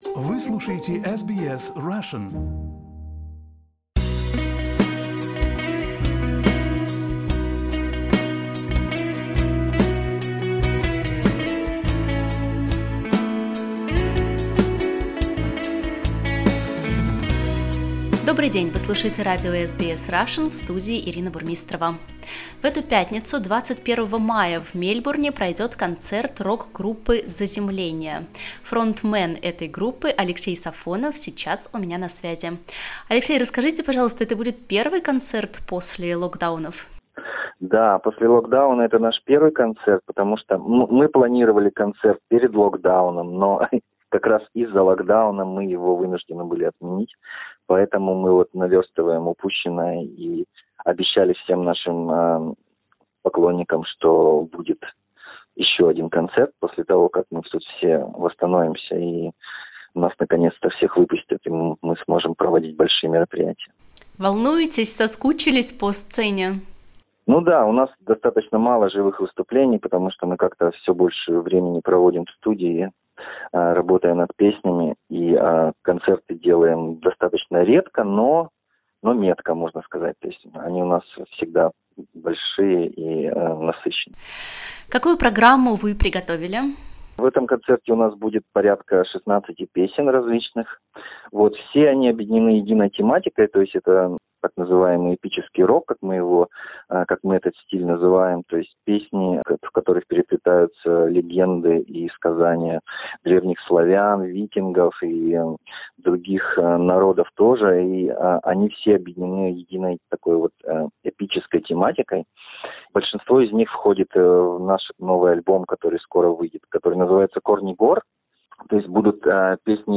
Russian-Australian rock-band "Landing on Zaz" will perform on Friday, 21/05. This interview is available in Russian only.